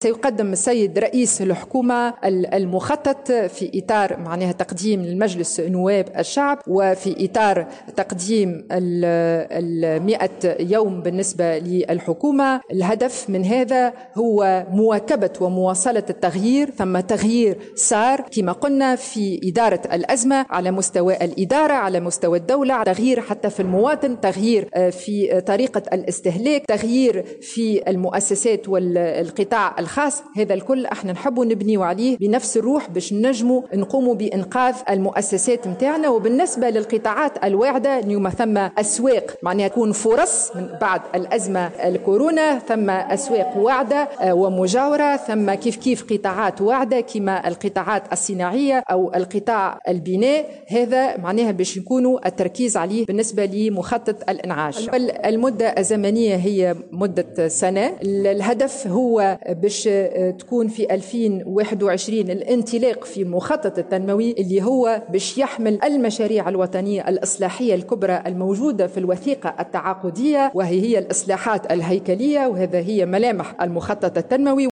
أفادت لبنى الجريبي، الوزيرة لدى رئيس الحكومة المكلفة بالمشاريع الوطنية الكبرى في تصريح لموفدة "الجوهرة اف ام"، اليوم الاثنين، بأن رئيس الحكومة الياس الفخفاخ يستعدّ لعرض مخطط للانعاش الاقتصادي على مجلس نواب الشّعب بمناسبة مرور 100 يوم على عمل الحكومة.